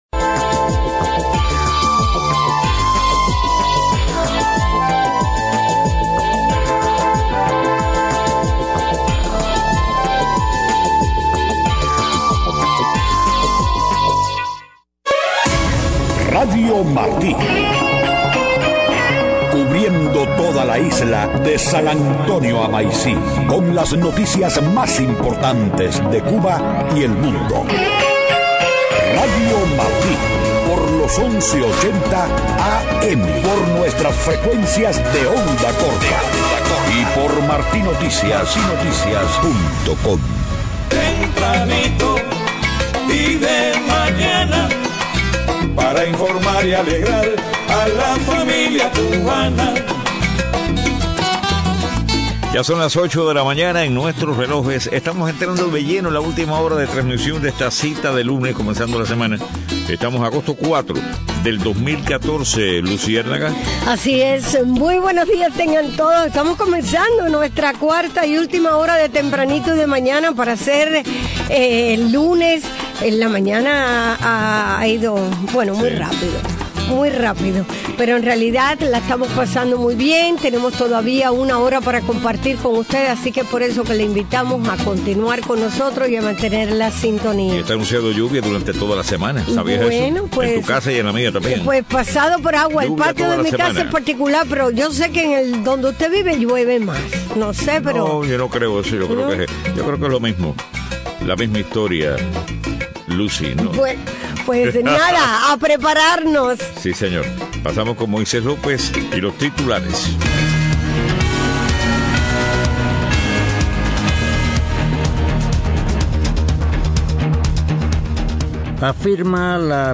8:00 a.m. Noticias: Legisladora Ileana Ros-Lethinen afirma que programas de EEUU para promover libertad en Cuba no son secretos. Embajada británica en La Habana anuncia becas de estudios de posgrado para profesionales cubanos en negocios, comunicación y religión.